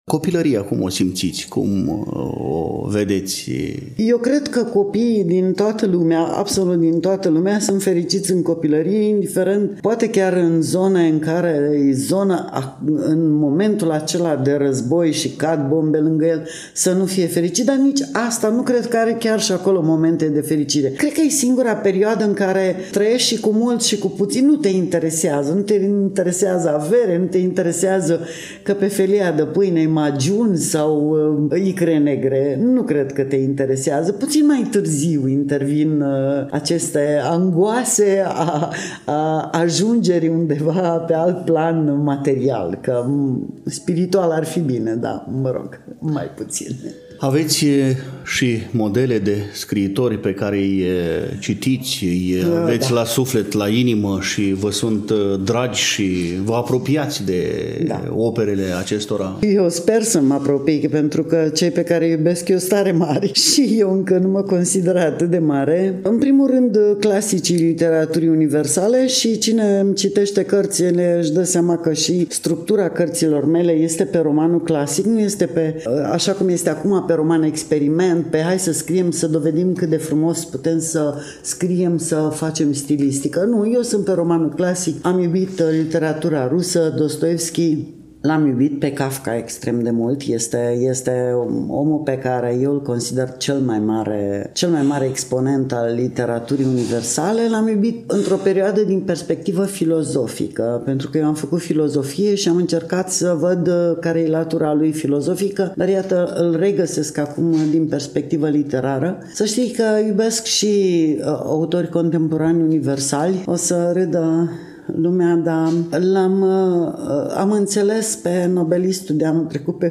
Evenimentul s-a desfășurat la Iași, nu demult, în librăria cafenea „Orest Tafrali” din incinta Universității „Alexandru Ioan Cuza”.